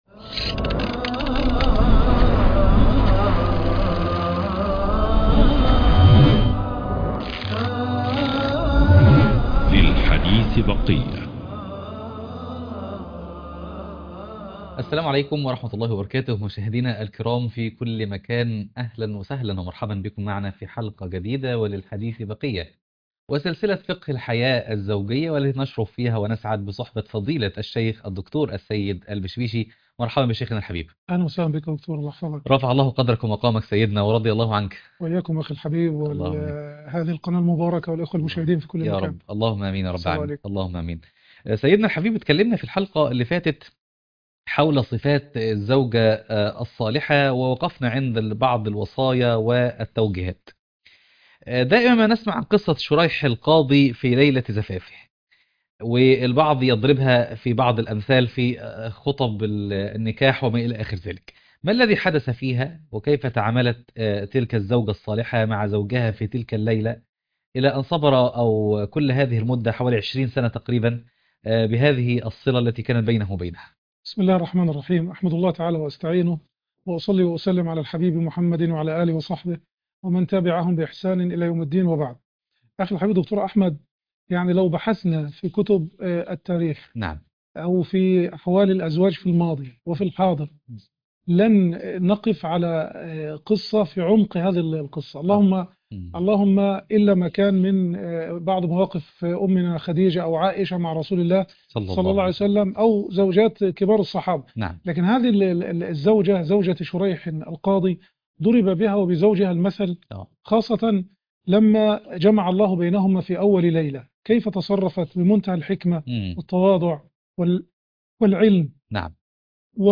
يحاوره